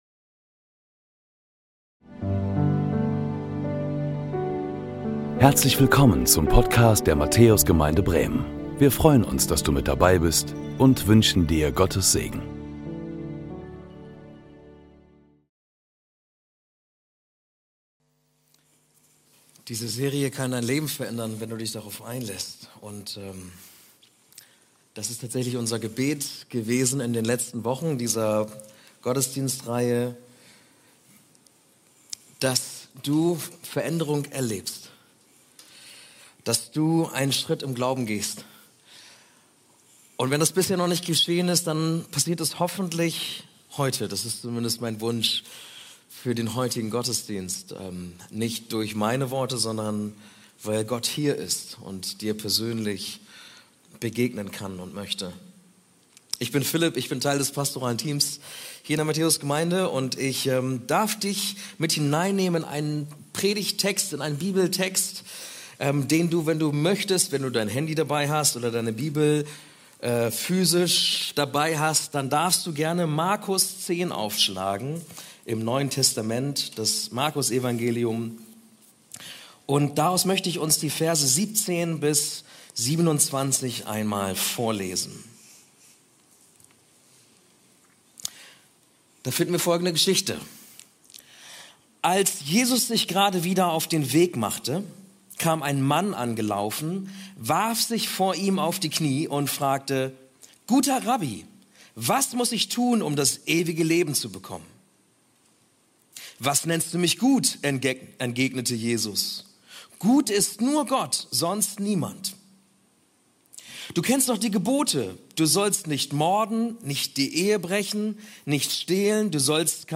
Predigten der Matthäus Gemeinde Bremen